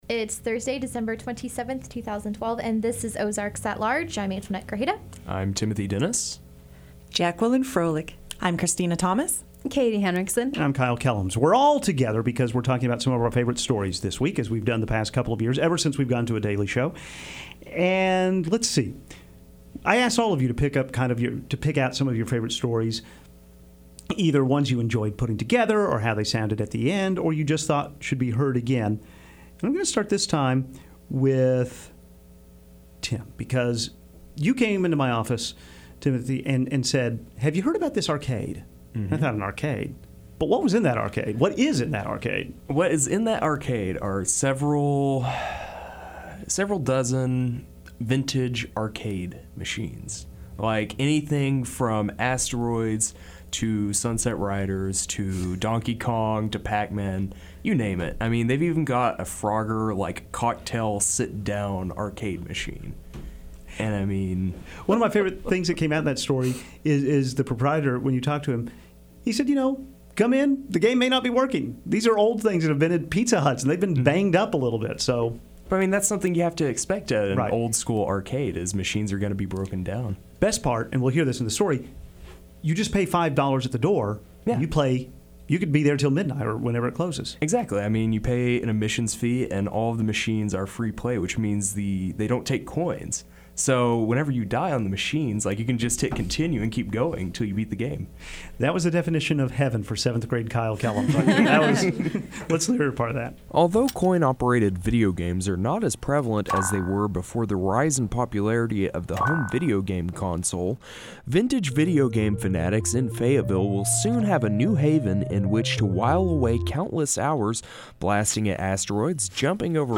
Audio: oalweb122712.mp3 Today we grab some more of our favorite stories from 2012 and listen to them again. Choice on today's program include: a retro arcade in Fayetteville , a music park underway in Eureka Springs , the true story of a player-piano genius , live fiddles in our studio , renters' rights in Arkansas and a memorable trip to a school cafeteria .